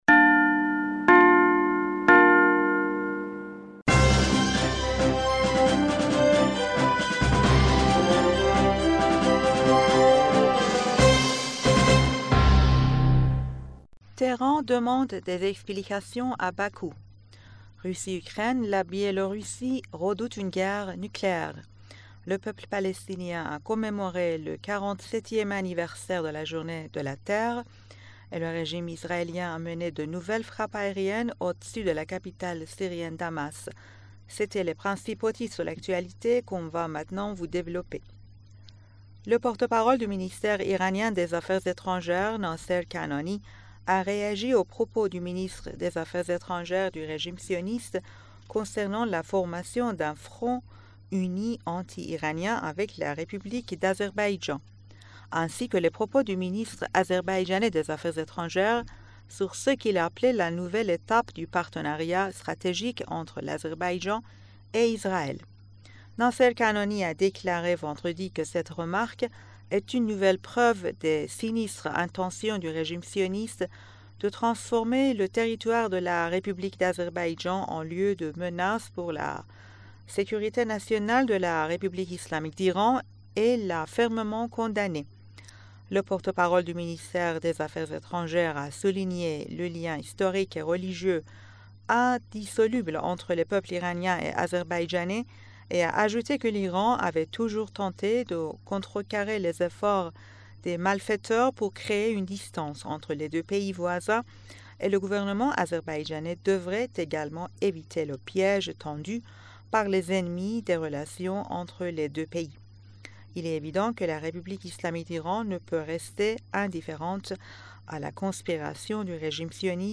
Bulletin d'information du 31 Mars